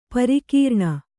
♪ parikīrṇa